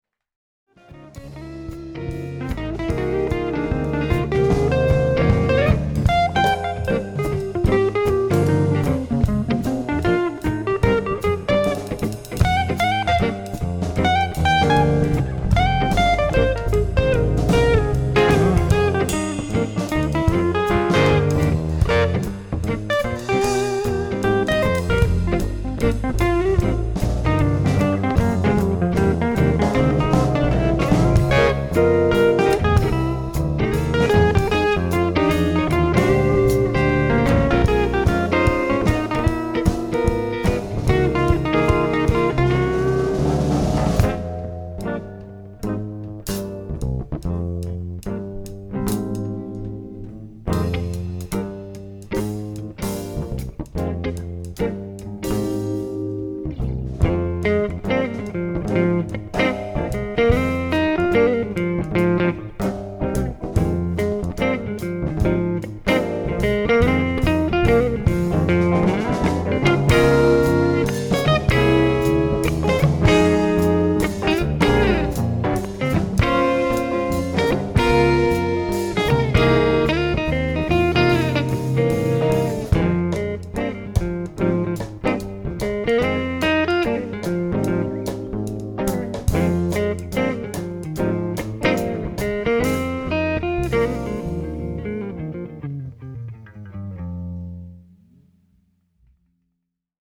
le répertoire jazz-blues (avec batterie) :
Enregisté en concert à la Fête de la musique le